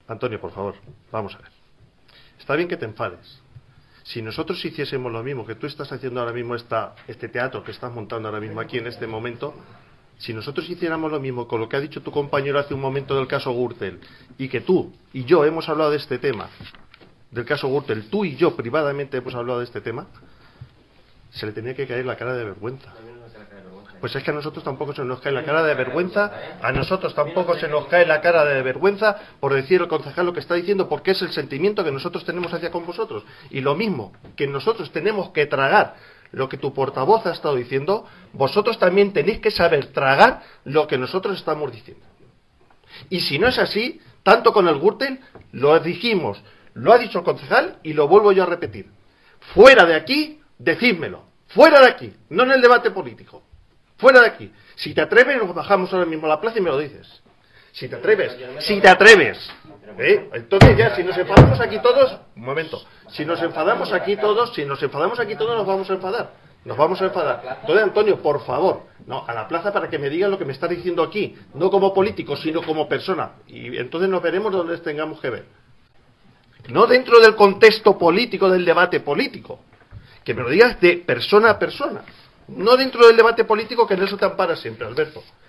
• Alberto Iglesias acusa a Vicente Aroca de utilizar un tono "pandillero y prepotente" cuando el alcalde lo retó, al grito de "si te atreves", a debatir en la calle sobre la concesión del servicio de recogida de basuras
Es «el tono más pandillero y tabernero que he oído en mi vida», ha escrito Alberto Iglesias en las redes sociales y ha calificado las palabras de Aroca de «amenazantes y prepotentes».
vicente_aroca_pleno.mp3